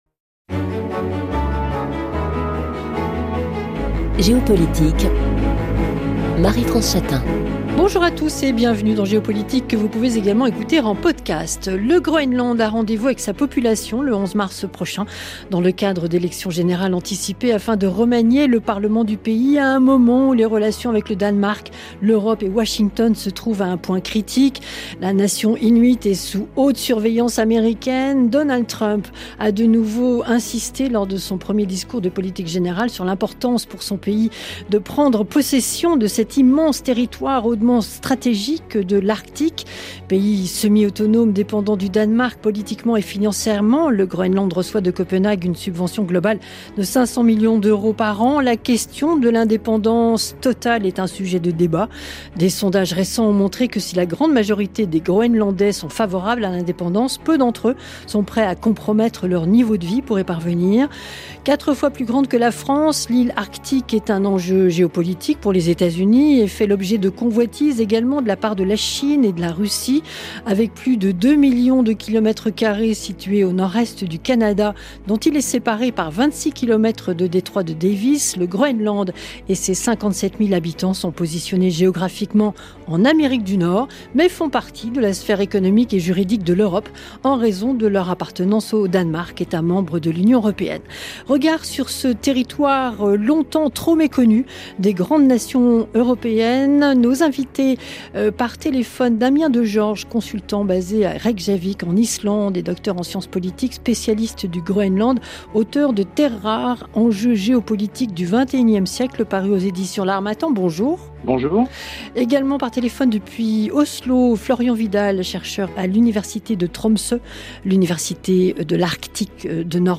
Les invités de Géopolitique confrontent leurs regards sur un sujet d’actualité internationale.